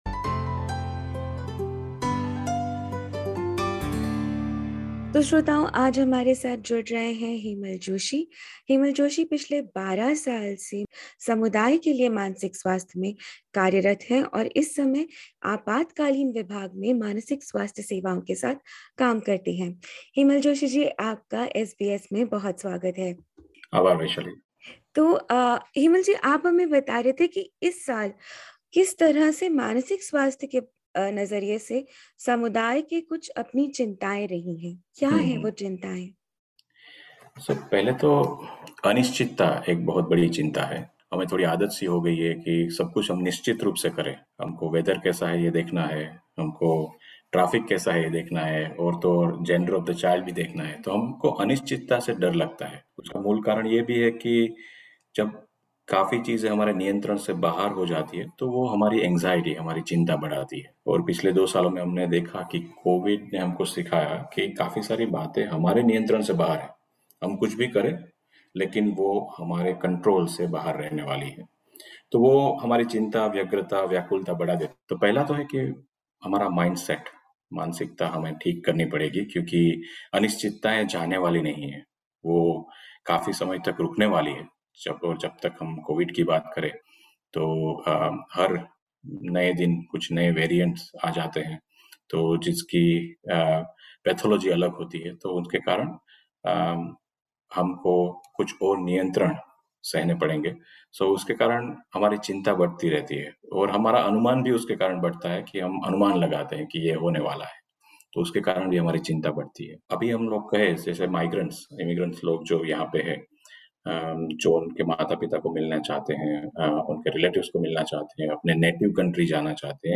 SBS हिंदी से चर्चा करते हुए वे बता रहे हैं कि बढ़ते कोरोना मामलों में समुदाय किस तरह से खुद को बेहतर संभाल सकता है, और कैसे कम कर सकते हैं हम अपना तनाव।